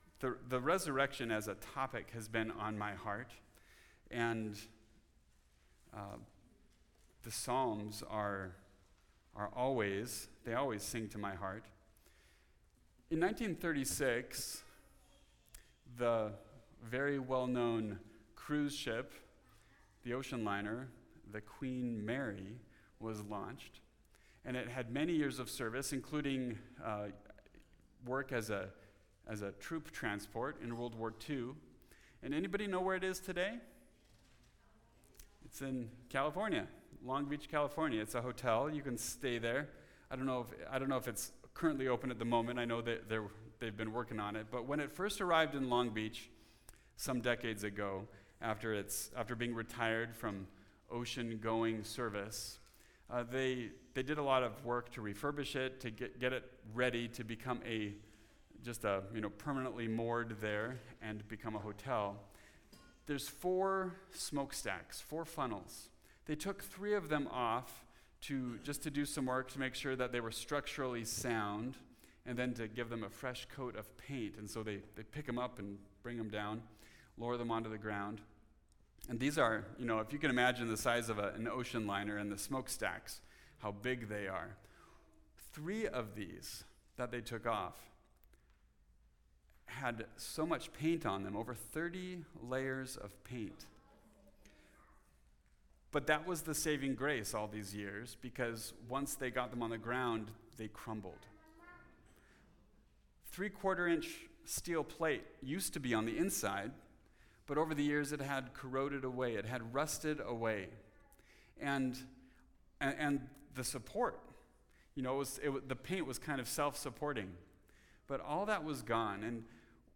Redeeming Praise Psalm 30 (cf. 2 Samuel 24; 1 Chronicles 21) – Mountain View Baptist Church